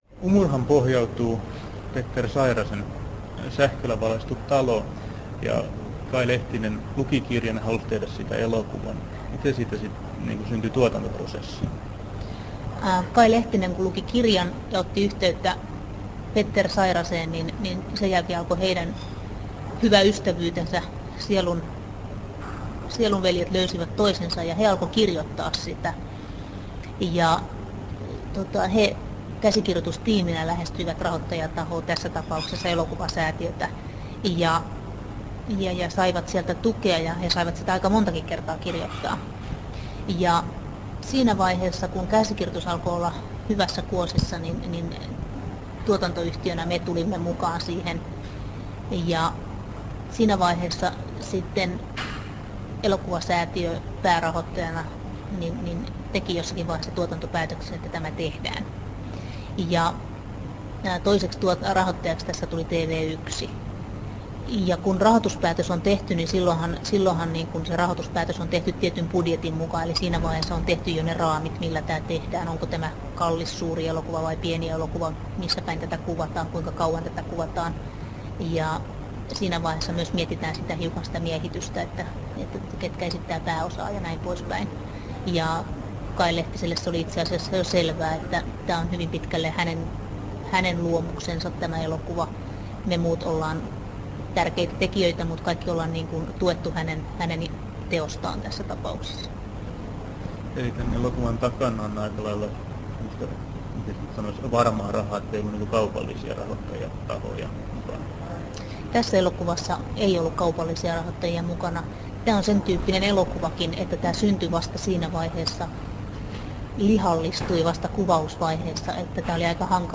Haastattelut